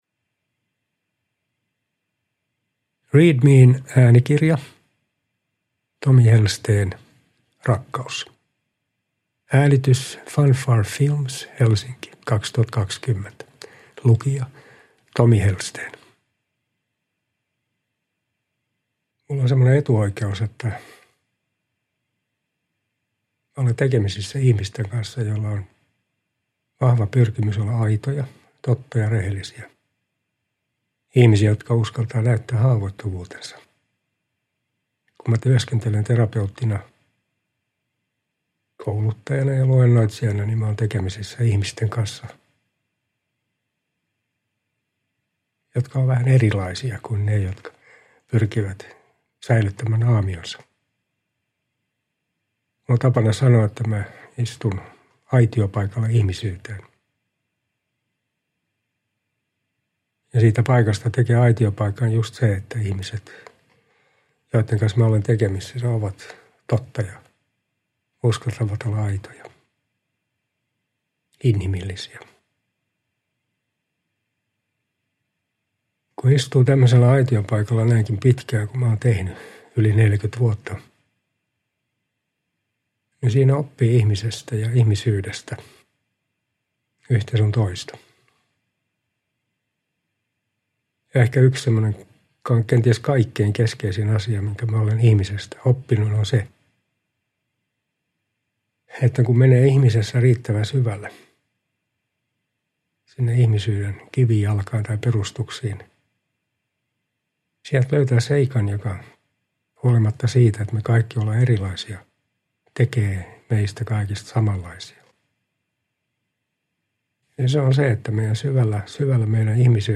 Rakkaus – Ljudbok